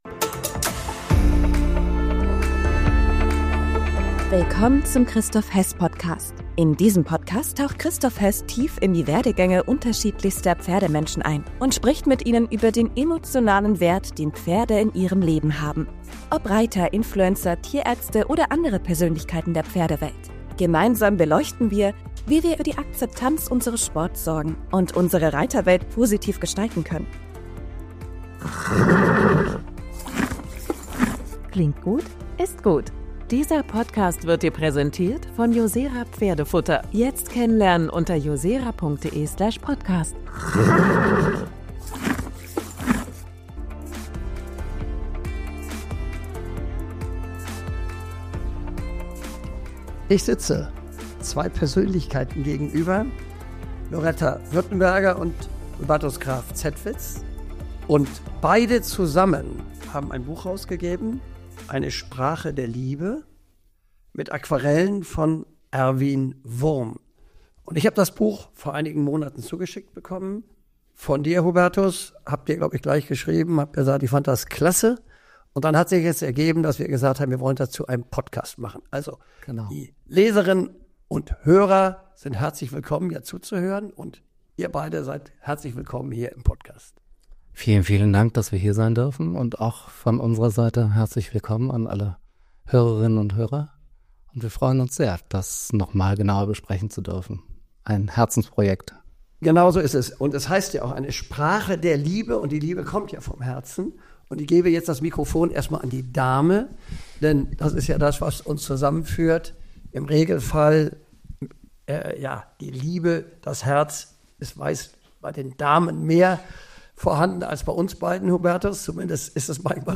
Ein Gespräch über Reitkultur, Werte und den Mut, Pferde – und Menschen – wirklich zu verstehen.